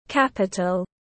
Thủ đô tiếng anh gọi là capital, phiên âm tiếng anh đọc là /ˈkæp.ɪ.təl/.
Capital /ˈkæp.ɪ.təl/